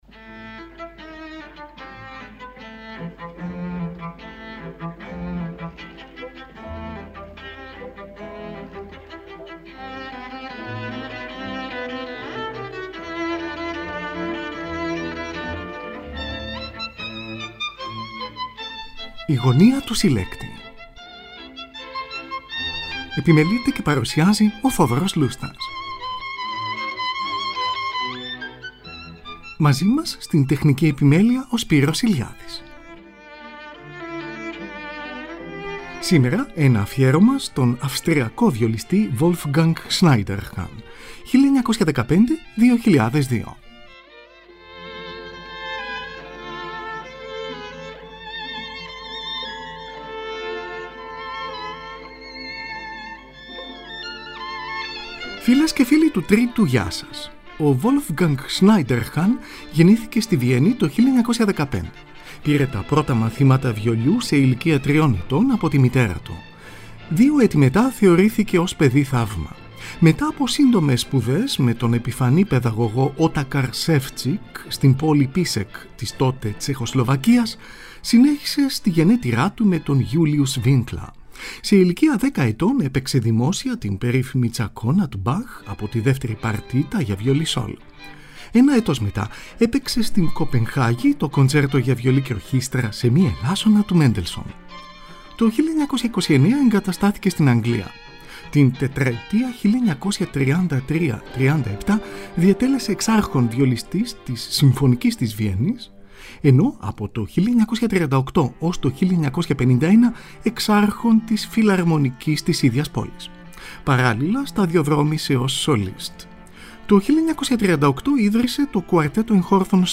Johann Sebastian Bach: κοντσέρτο για βιολί, έγχορδα και basso continuo, σε λα ελάσσονα, BWV 1041. Τον Wolfgang Schneiderhan συνοδεύουν μέλη της Φιλαρμονικής της Βιέννης υπό τον Hans Knappertsbusch. Ραδιοφωνική ηχογράφηση την πρώτη Ιουλίου 1944, κατά τη διάρκεια του Δευτέρου Παγκοσμίου Πολέμου.
Johannes Brahms: κοντσέρτο για βιολί, βιολοντσέλο και ορχήστρα, έργο 102. Σολίστ είναι ο βιολιστής Wolfgang Schneiderhan και ο βιολοντσελίστας Enrico Mainardi. Tην Ορχήστρα του Φεστιβάλ της Λουκέρνης διευθύνει ο Wilhelm Furtwängler, από ζωντανή ηχογράφηση, στις 24 Αυγούστου 1949.